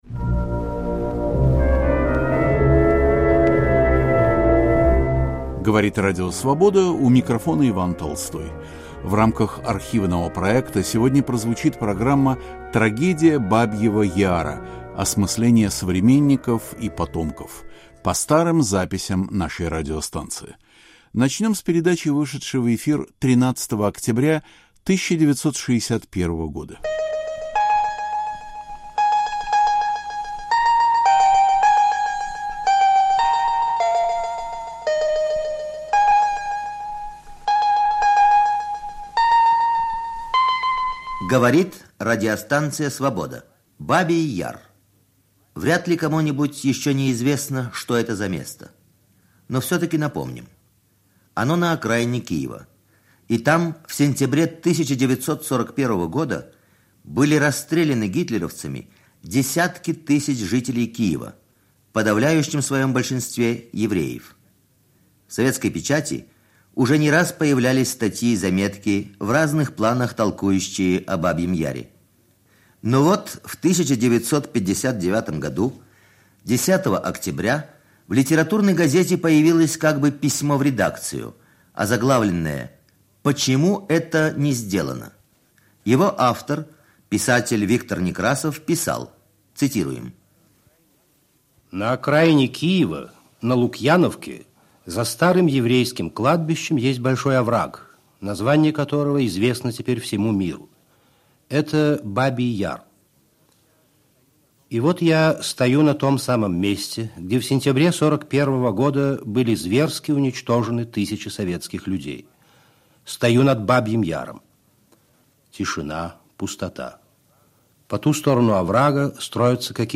В рамках архивного проекта - подборка интервью, выступлений и чтений за разные годы вещания.